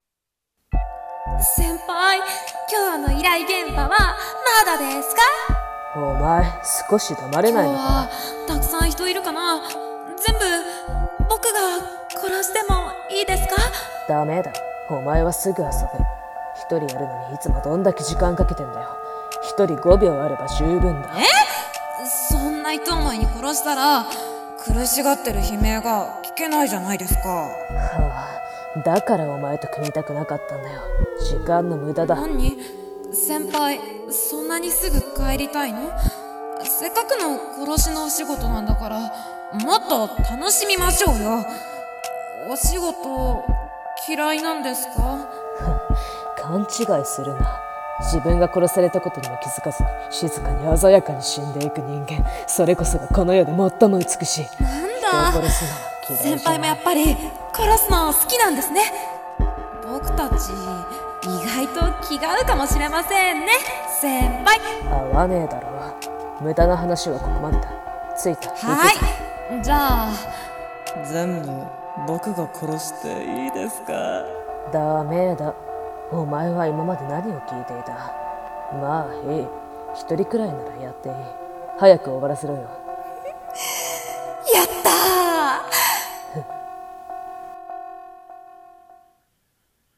【声劇台本】殺人鬼の先輩と後輩